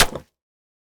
Minecraft Version Minecraft Version latest Latest Release | Latest Snapshot latest / assets / minecraft / sounds / block / mud_bricks / break2.ogg Compare With Compare With Latest Release | Latest Snapshot
break2.ogg